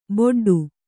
♪ boḍḍu